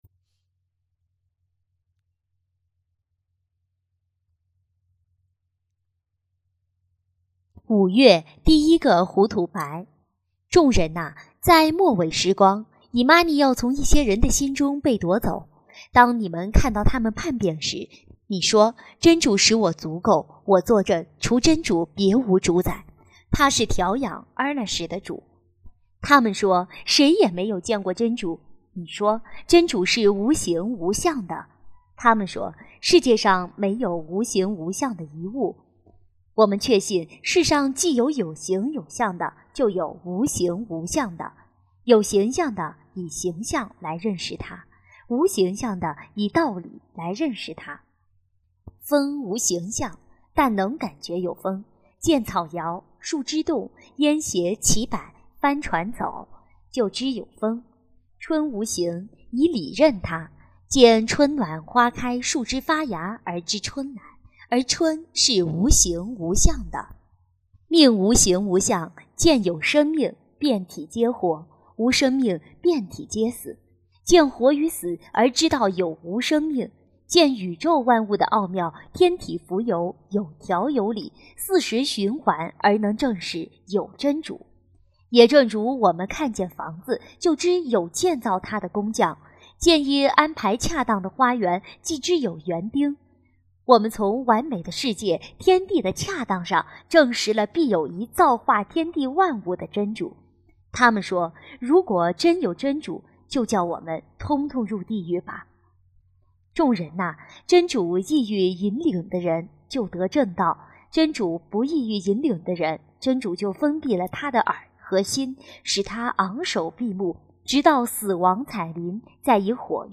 五月 止莫的来按瓦尼的第一个虎土白 - 《虎土白》讲义 - 真境绿翠网 - Powered by Discuz!